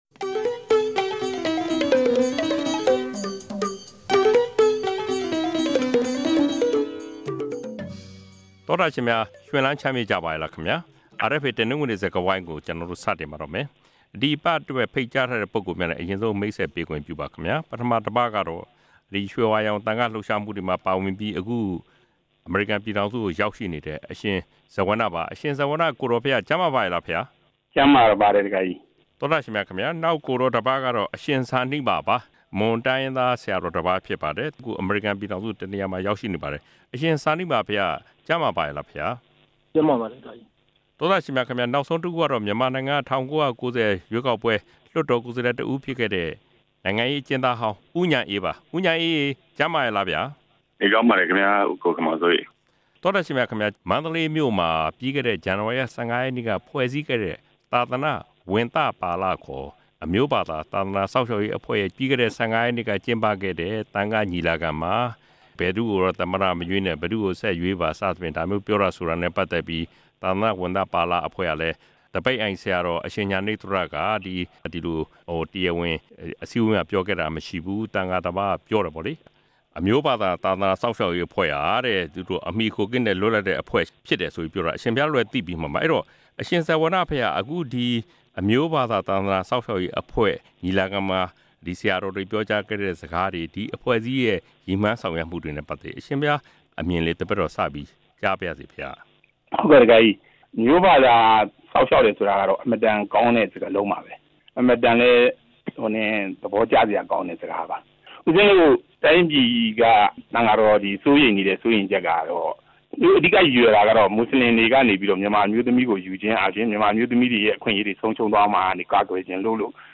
မန္တလေး သံဃာ့ညီလာခံအကြောင်း ဆွေးနွေးချက်